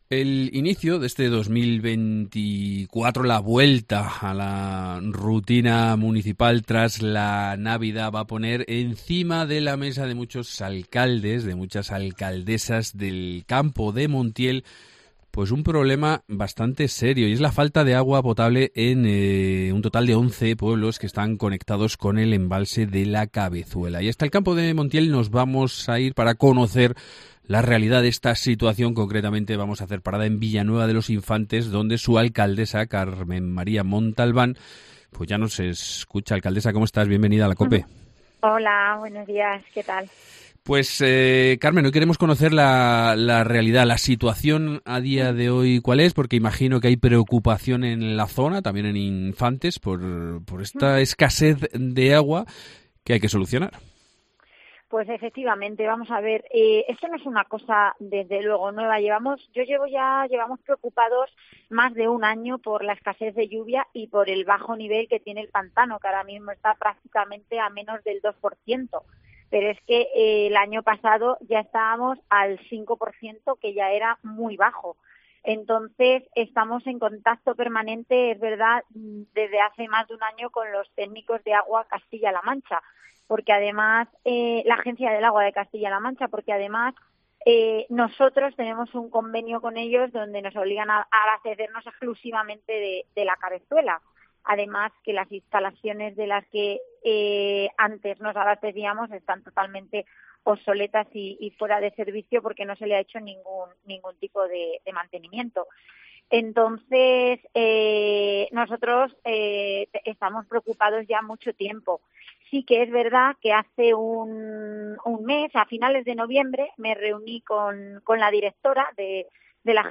AUDIO: Carmen María Montalbán, alcaldesa de Villanueva de los Infantes